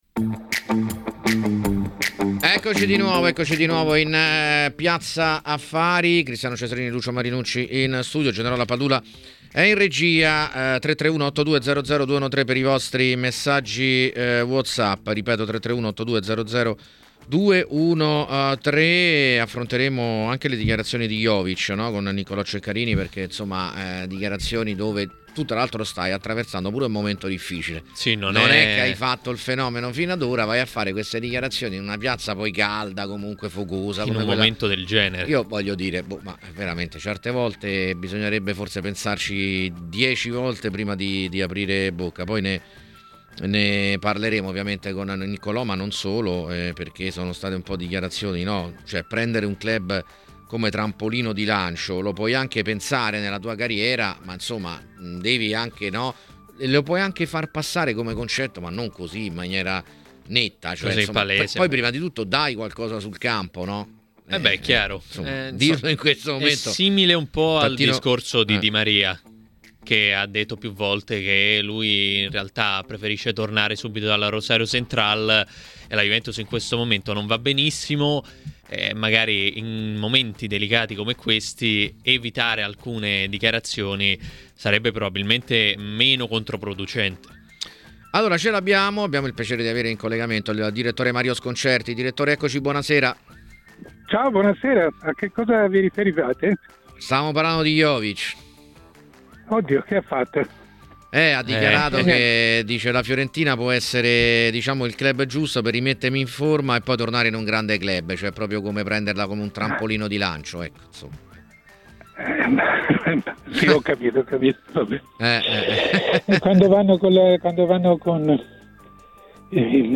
Il giornalista Mario Sconcerti è intervenuto ai microfoni di 'TMW Radio' nel corso della trasmissione 'Piazza Affari'.